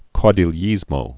(kôdēl-yēzmō, -dē-yēz-, kou-)